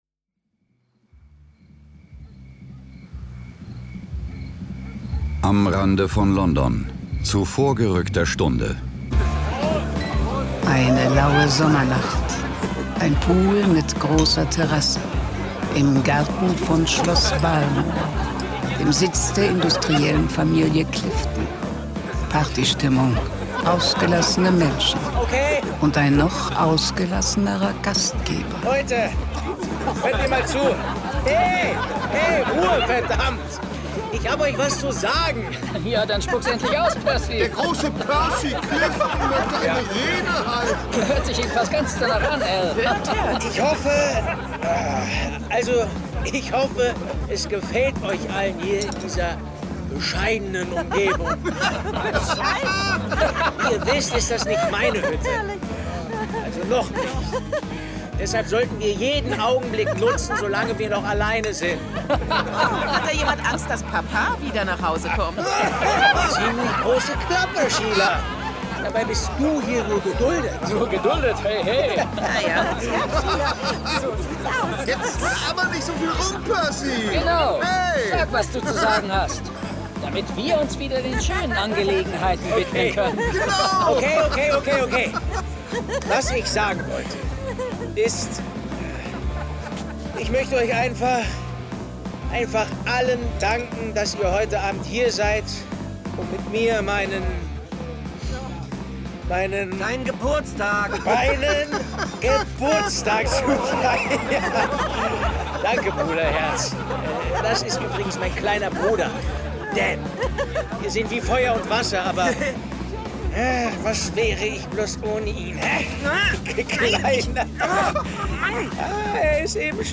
John Sinclair Classics - Folge 41 Der schwarze Würger. Hörspiel.